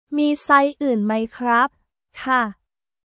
ミー サイズ ウーン マイ クラップ/カー